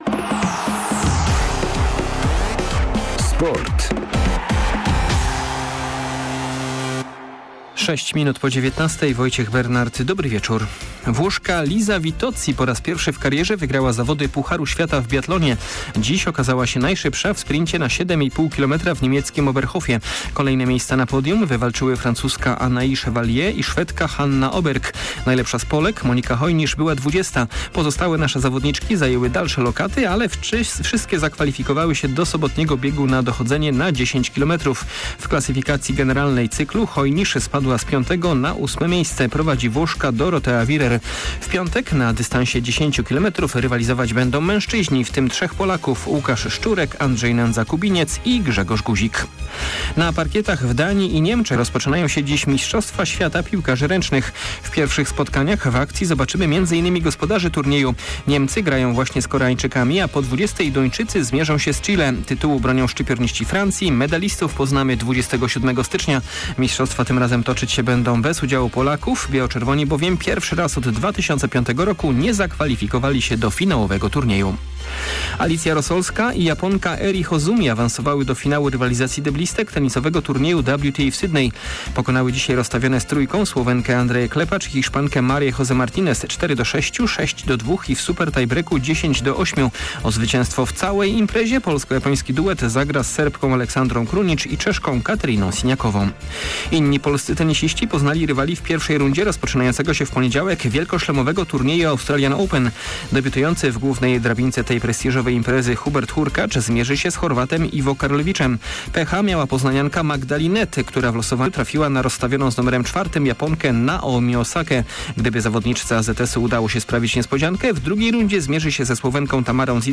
10.01. serwis sportowy godz. 19:05
W serwisie tym razem między wyniki z biathlonowych tras oraz tenisowych kortów. Zapowiemy także rozpoczynające się na parkietach w Danii i Niemczech mistrzostwa świata piłkarzy ręcznych. Na koniec posłuchamy sportowego podsumowania 2018 roku w wykonaniu lekkoatletki AZS Poznań - Joanny Fiodorow.